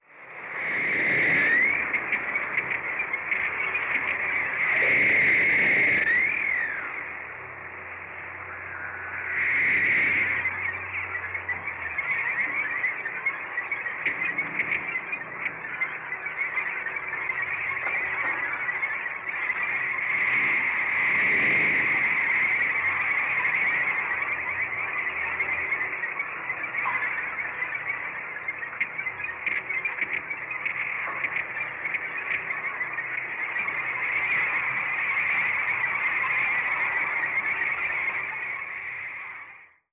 На этой странице собраны их уникальные вокализации: от низкочастотных стонов до резких щелчков эхолокации.
Шепот стаи кашалотов